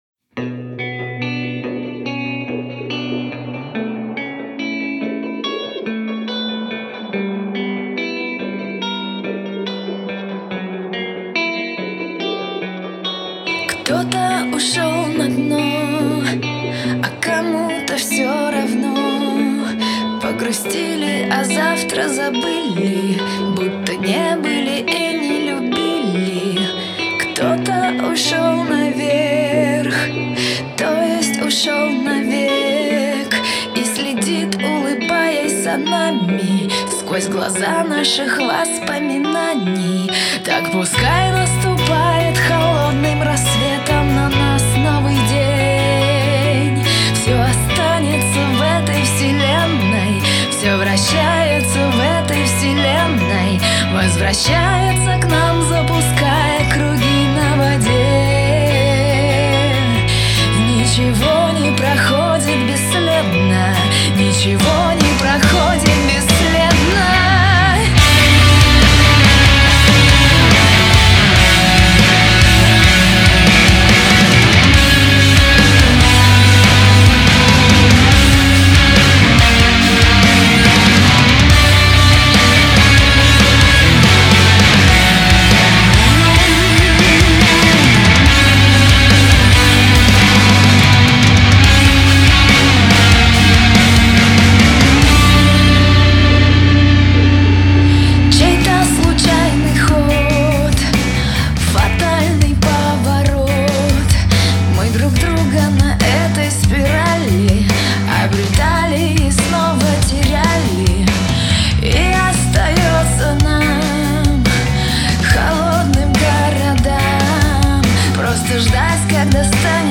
Рок-группа